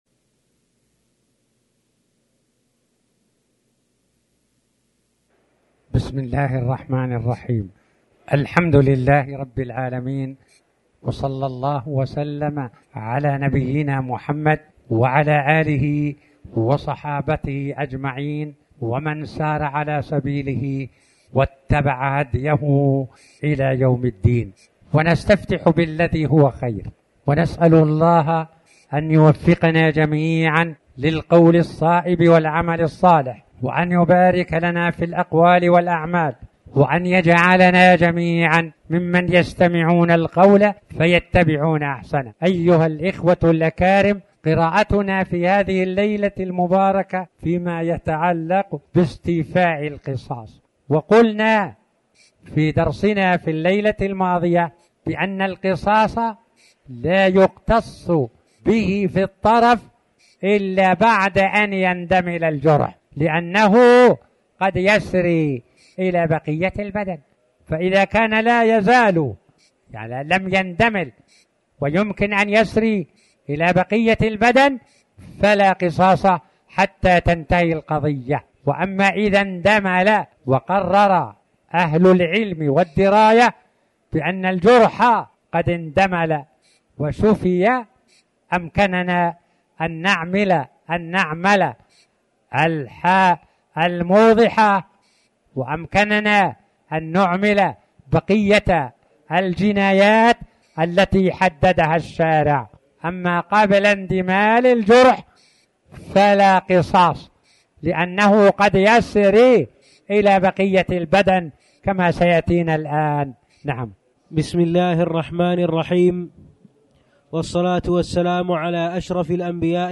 تاريخ النشر ١ شعبان ١٤٣٩ هـ المكان: المسجد الحرام الشيخ